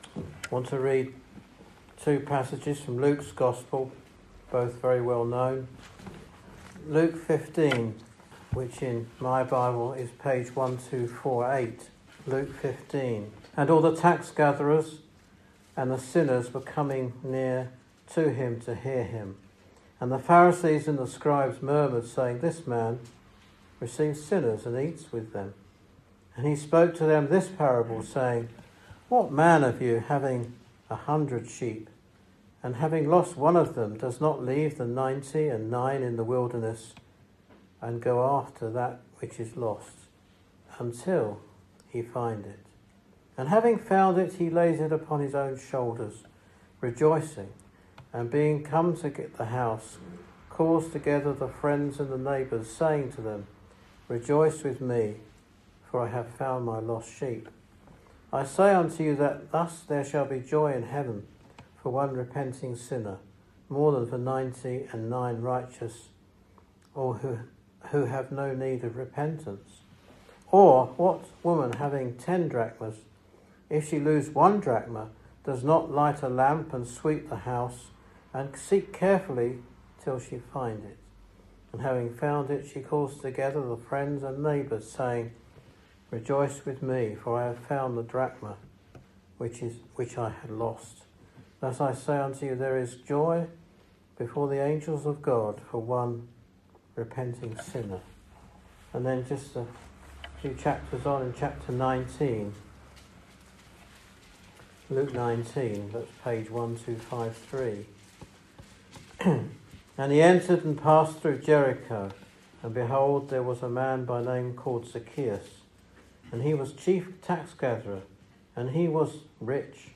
This gospel preaching is based on the parables from Luke 15, focusing on the theme of the lost and the seeking. Through the parables of the lost sheep and the lost coin, and the account of Zacchaeus, it sets forth the work of the Lord Jesus Christ, who came to seek and to save that which was lost.